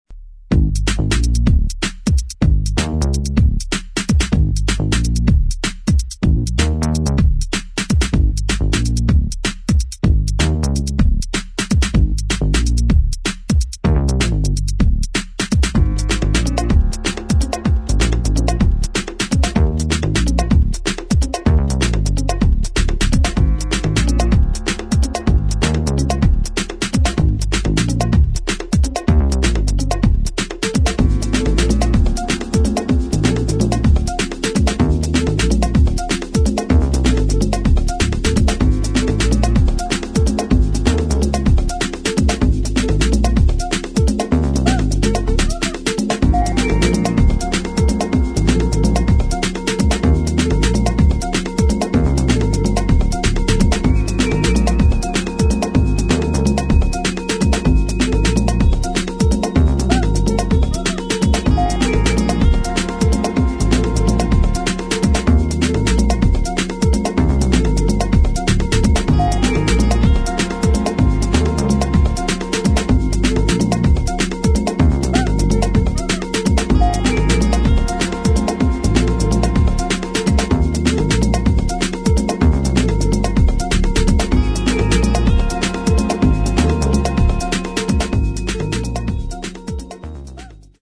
[ FUTURE JAZZ | LATIN | BROKEN BEAT ]